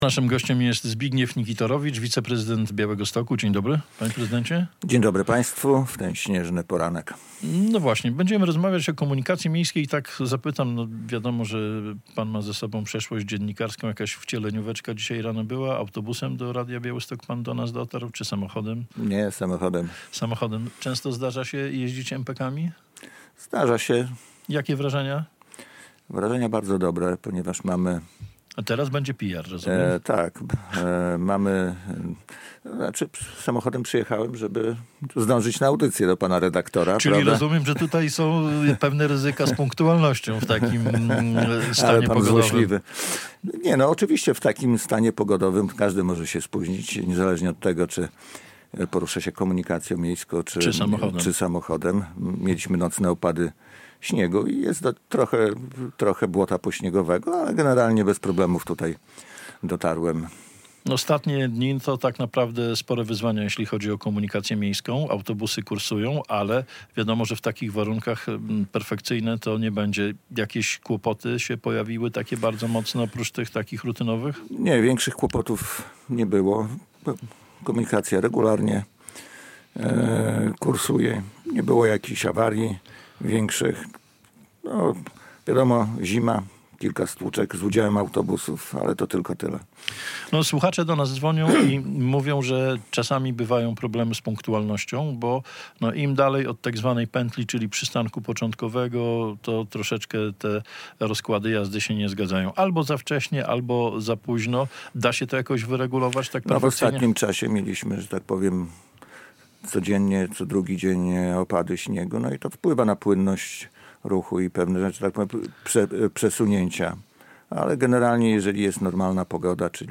Radio Białystok | Gość | Zbigniew Nikitorowicz [wideo] - zastępca prezydenta Białegostoku
zastępca prezydenta Białegostoku